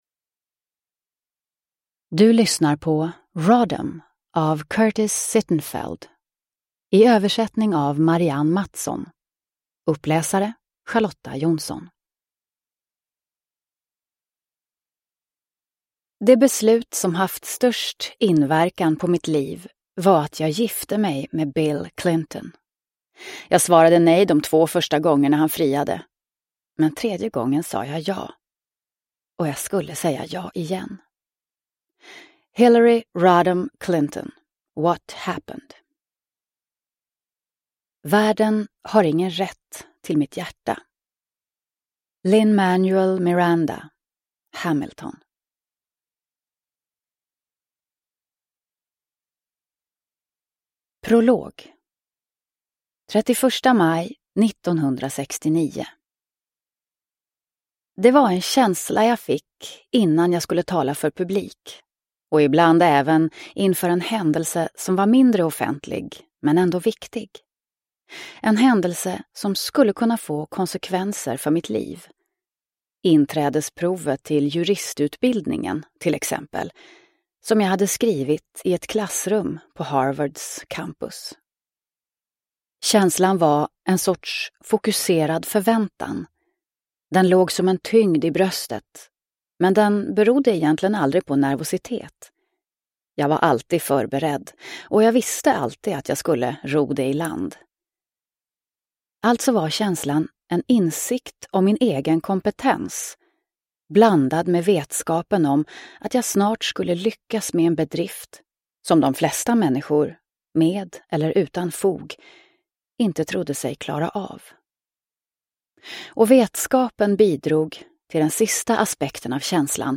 Rodham – Ljudbok – Laddas ner